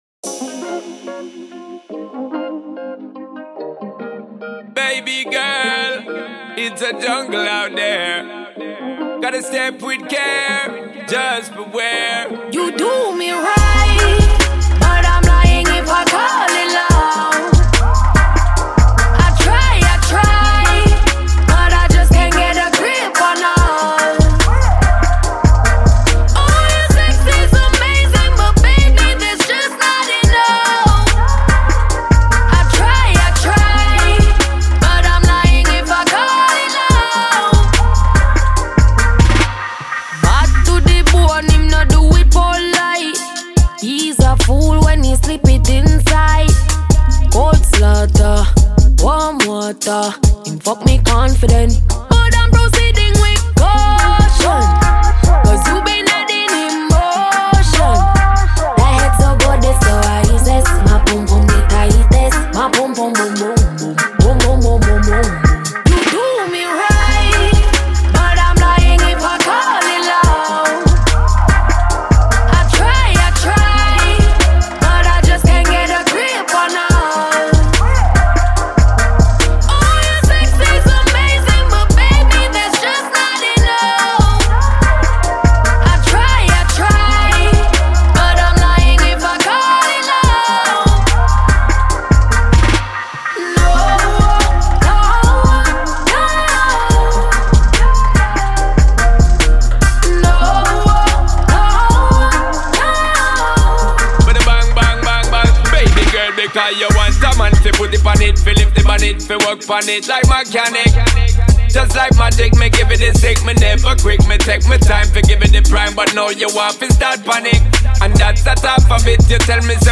Dancehall/HiphopMusic